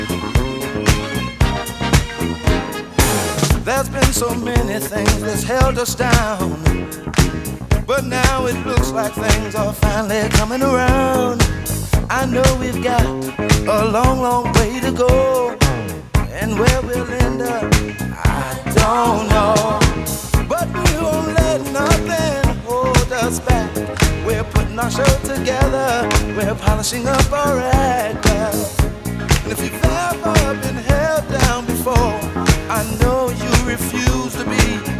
Жанр: Рок / R&B / Соул / Фанк